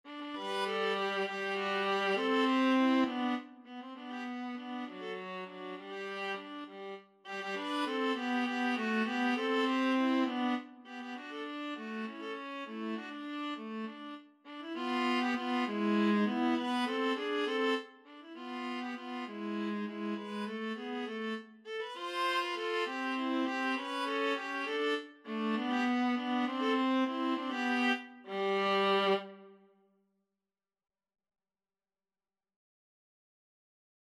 3/8 (View more 3/8 Music)
Classical (View more Classical Viola Duet Music)